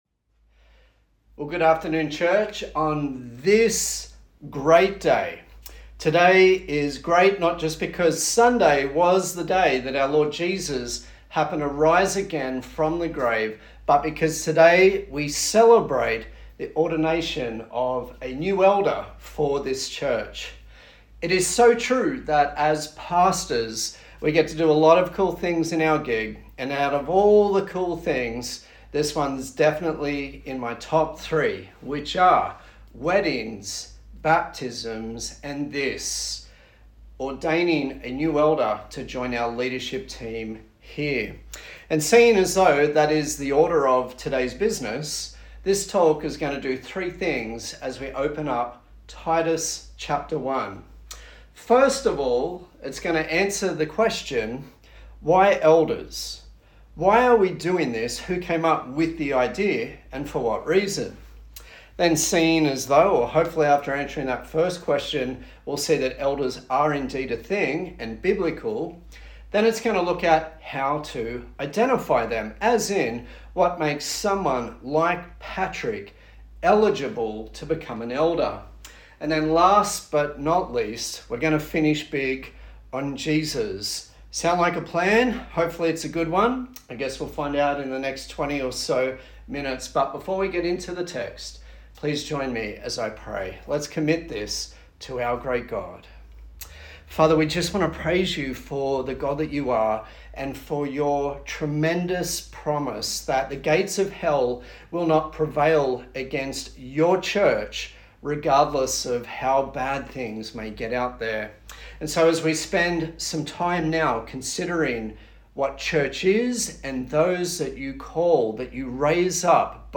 A sermon on ordination from Titus 1
Service Type: Sunday Service